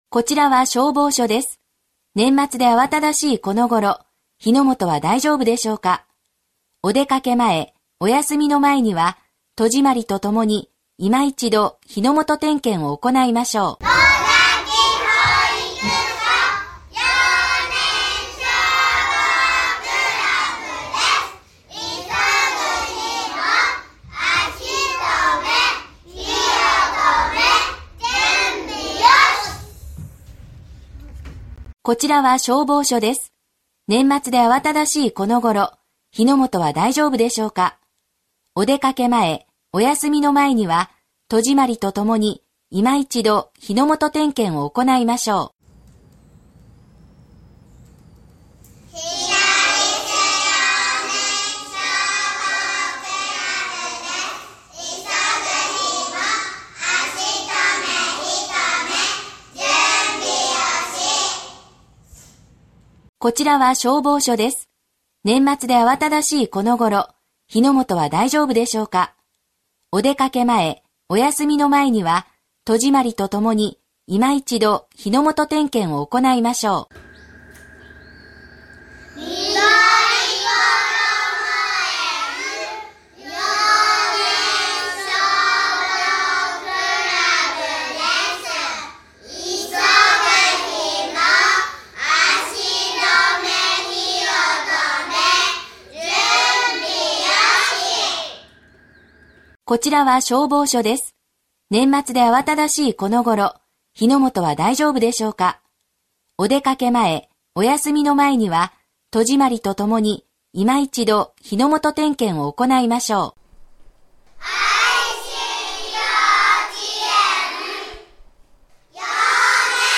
幼年消防クラブ員のかわいい元気いっぱいの声で録音した全国統一防火標語「急ぐ日も　足止め火を止め　準備よし」を使用し、消防車で巡回広報を行います。
歳末特別警戒に係る幼年消防クラブ員による消防車両巡回広報の音声